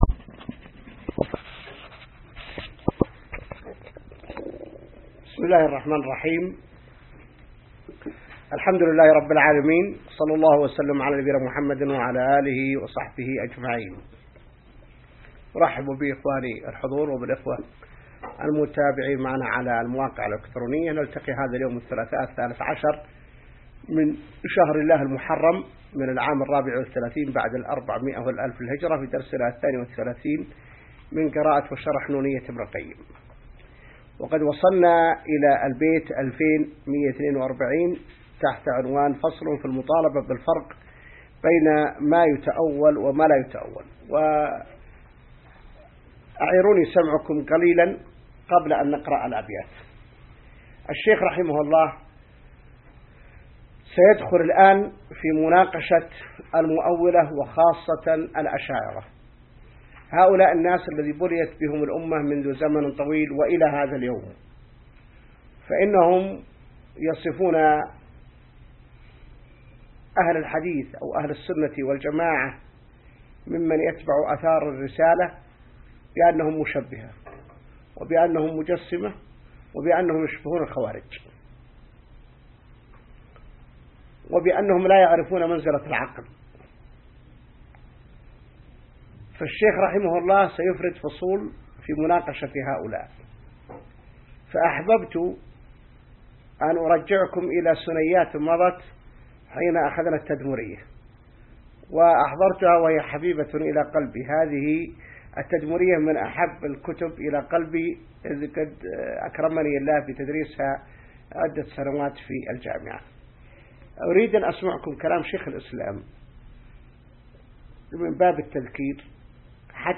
الدرس 32 من شرح نونية ابن القيم | موقع المسلم